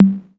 Tom Fiasco 2.wav